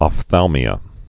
(ŏf-thălmē-ə, ŏp-)